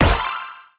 Appear_Scatter_Sound.mp3